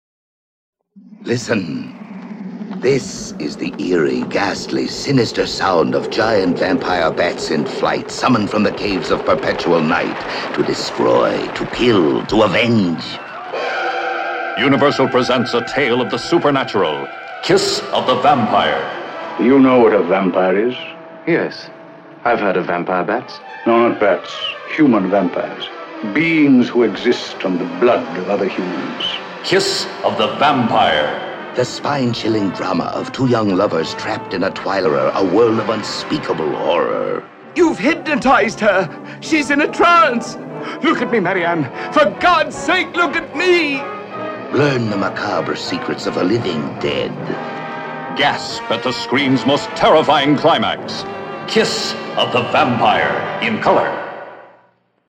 Radio Spots
The radio spots presented here are atmospheric, and capture the thrills in store for the theatergoer.